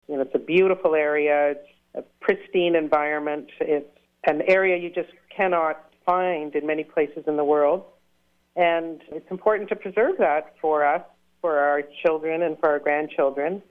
What you will be hearing is audio from an interview recorded May 22nd of 2022.